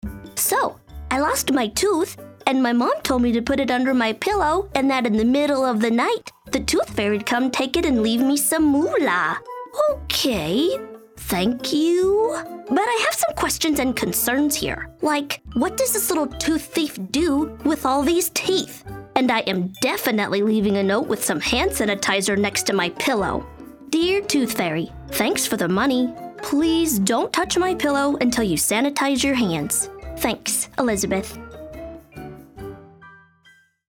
Female
My voice is youthful, engaging, and upbeat, with a clear and lively tone.
Character / Cartoon
Animated , Cute , Fun , Youthf